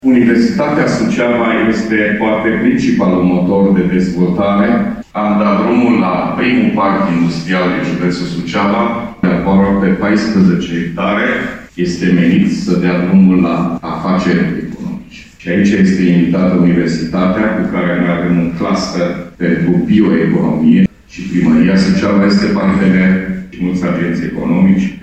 Prezent la ședința festivă 30 ani de la înființarea Universității Ștefan cel Mare Suceava, președintele Consiliului Județean GHEORGHE FLUTUR a subliniat că instituția de învățământ superior rămâne “principalul motor de dezvoltare al zonei”.